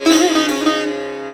SITAR GRV 05.wav